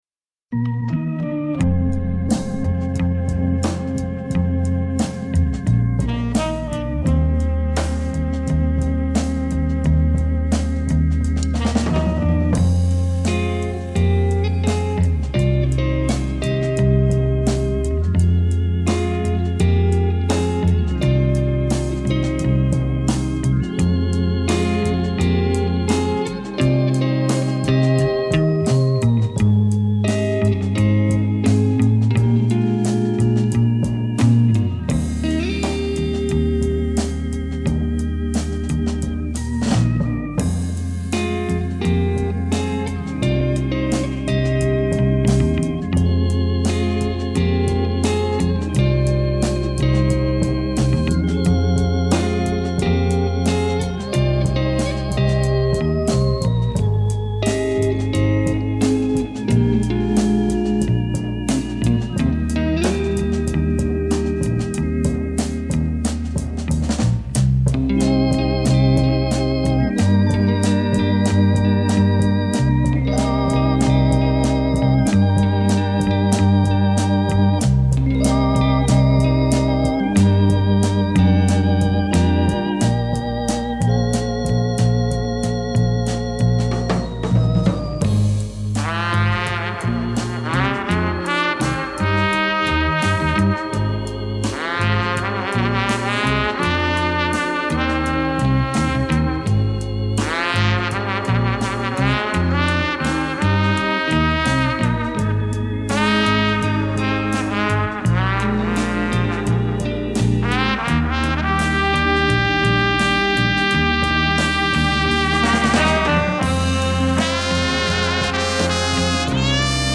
Более качественной записи,чем эта, мне не попадалось.